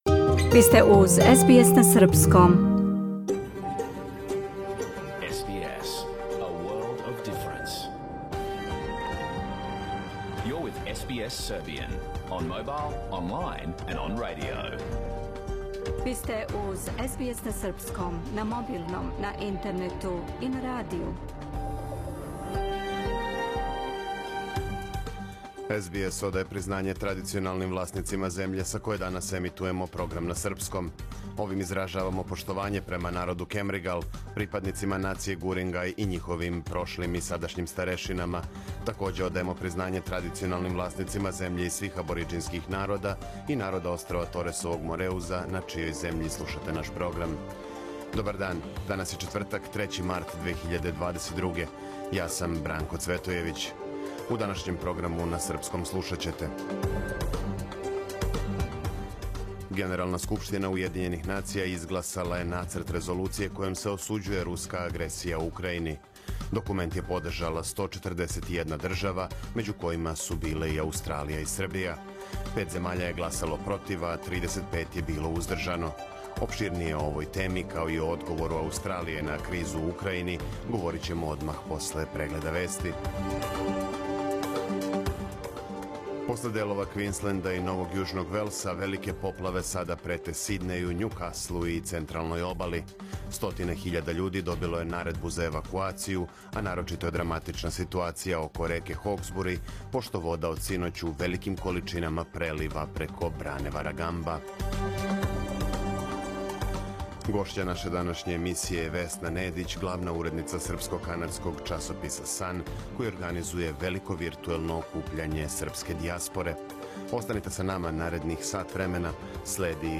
Програм емитован уживо 3. марта 2022. године